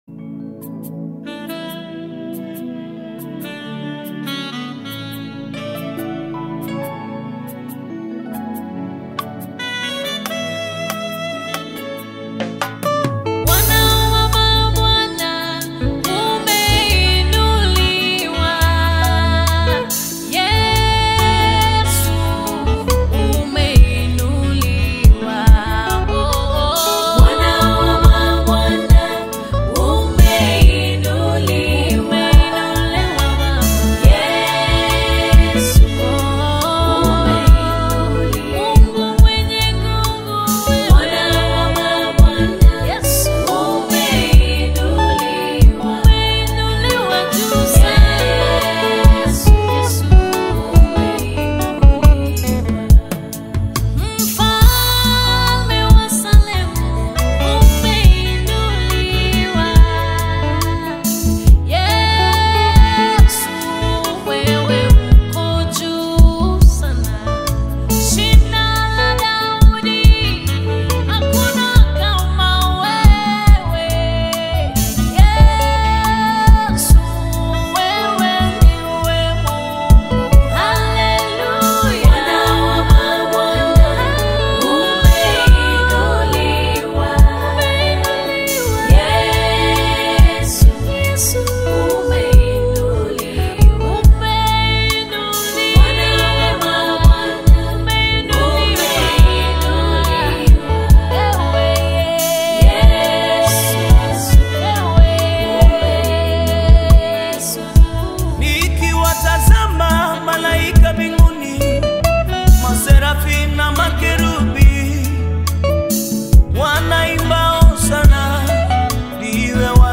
Universal Gospel
The prominent Kenyan gospel music artiste and worship leader
a mesmerising melody